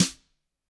Index of /90_sSampleCDs/ILIO - Double Platinum Drums 1/CD4/Partition C/GRETCHBRSNRD